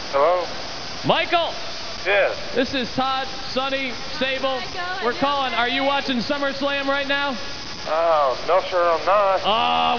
God I love live television.